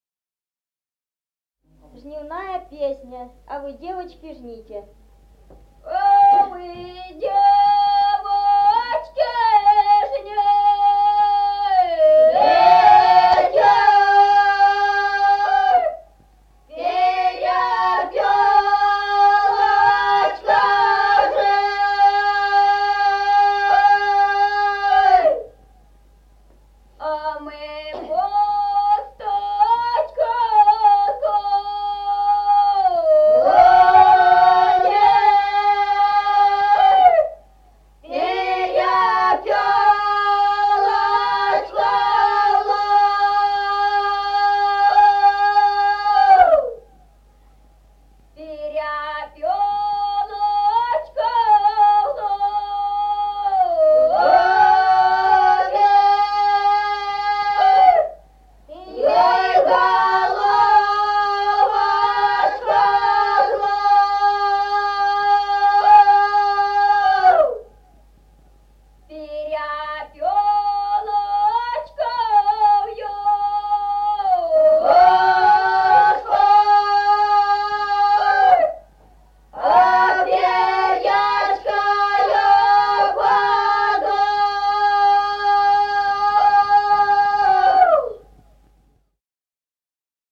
Музыкальный фольклор села Мишковка «А вы, девочки, жните», жнивная.